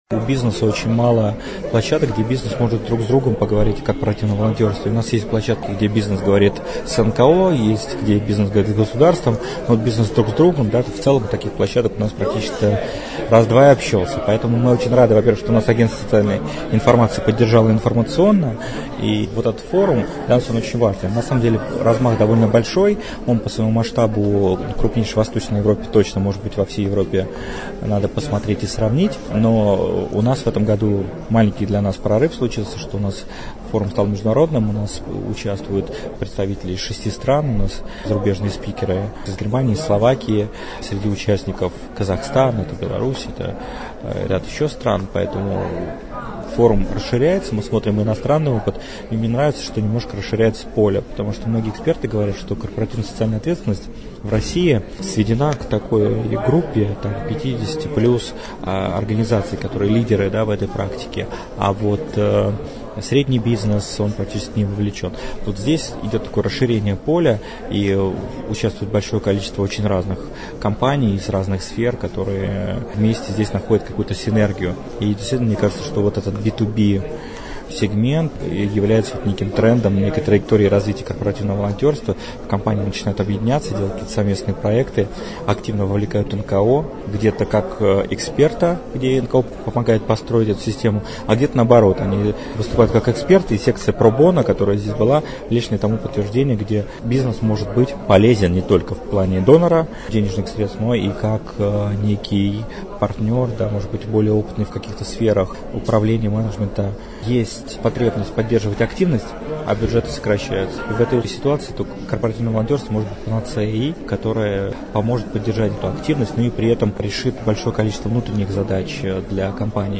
«Расскажем» — аудиопроект Агентства социальной информации: живые комментарии экспертов некоммерческого сектора на актуальные темы.